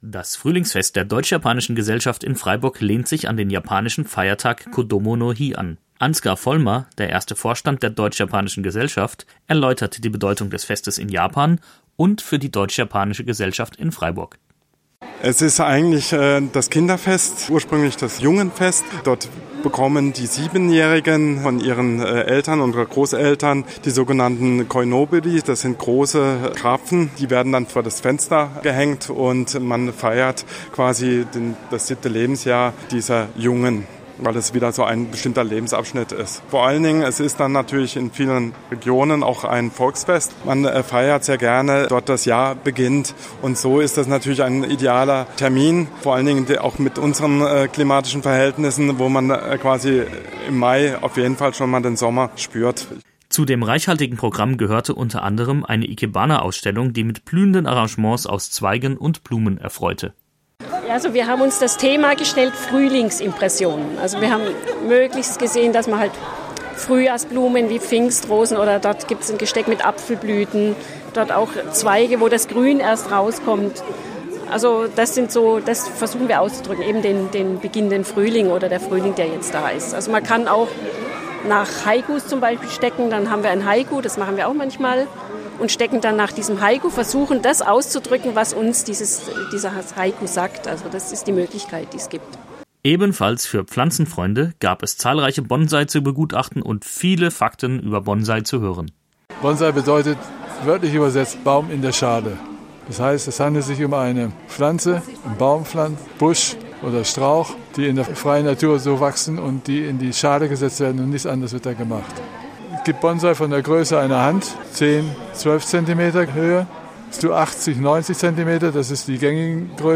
Gebauter Beitrag
Einmal im Jahr lädt die Deutsch-Japanische-Gesellschaft in Freiburg zum Frühlingsfest ein. Im Bürgerhaus am Seepark gibt es ein reichhaltiges Programm, dass den Besucherinnen und Besuchern japanische Kultur und Lebensweise näher bringen will.